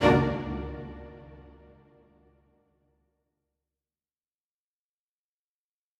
Strings Hit 5 Spiccato
Bring new life to your videos with professional orchestral sounds.
A spiccato is one of the shortest and fastest sounds that a string instrument can make.  In this sample, you hear four sections of four different instruments from the orchestra which are violins, violas, violoncellos and double basses.
Strings-Hit-5-Spiccato.wav